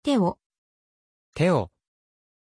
Pronuncia di Teo
pronunciation-teo-ja.mp3